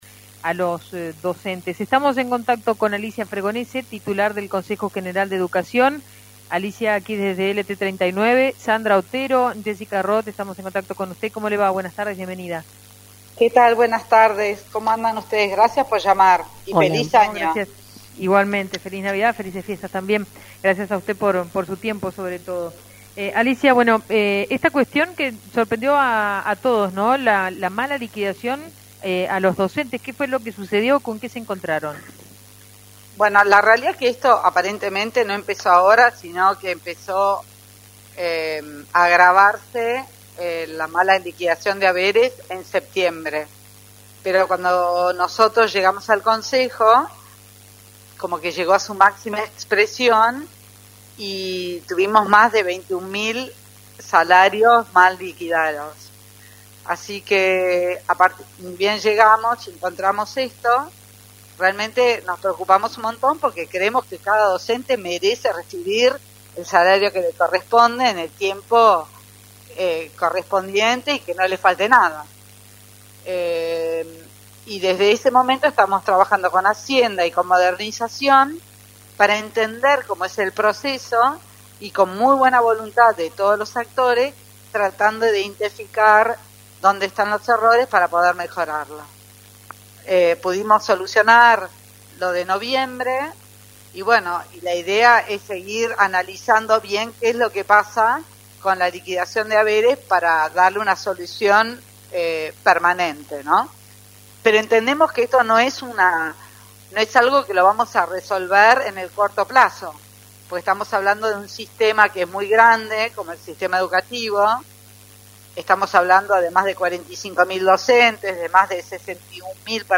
Así lo adelantó Alicia Fregonese, Presidenta del Consejo General de Educación.